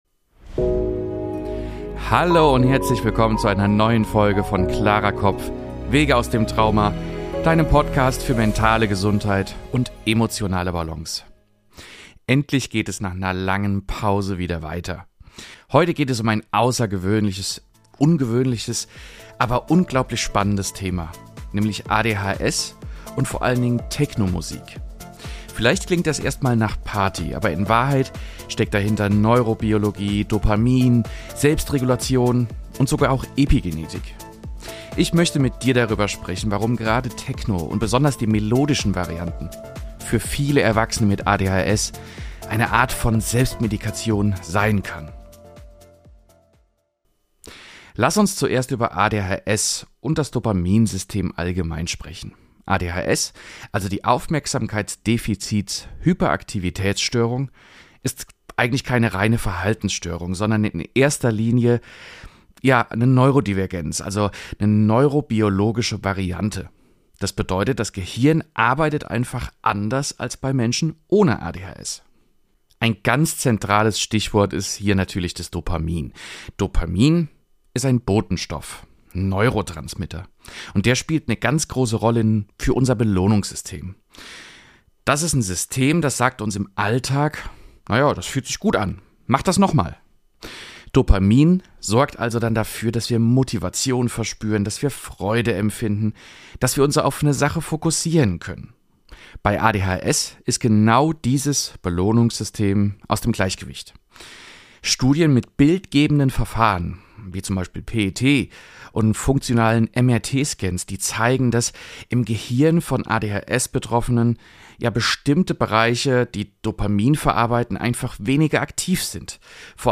Ich erkläre dir die wissenschaftlichen Hintergründe, wir hören passende Soundbeispiele, und du bekommst Einblicke, wie Musik nicht nur im Moment wirkt, sondern langfristig das Gehirn trainieren kann.